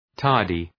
{‘tɑ:rdı}